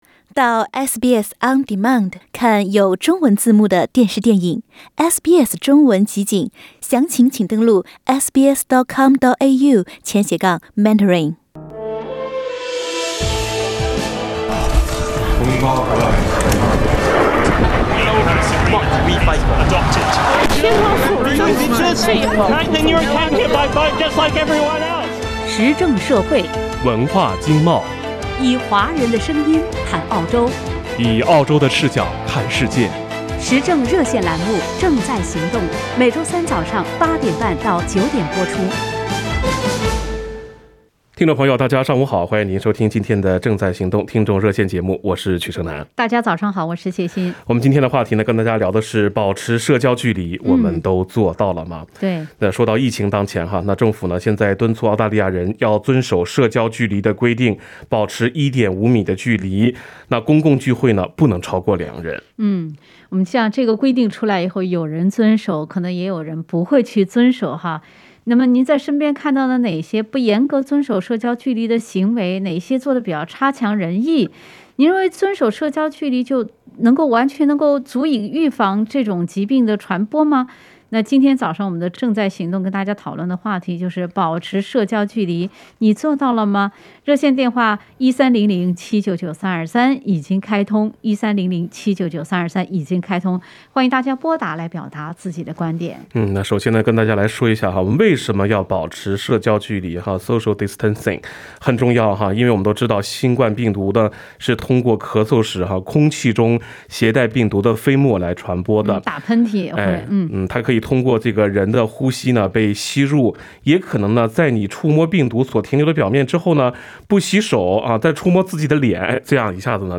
澳洲人对“社交距离”规定遵守得怎么样？“社交距离”能预防新冠病毒传播吗？点击上方图片收听澳洲华人听众的评论。